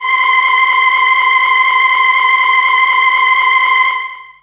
55be-syn19-c5.wav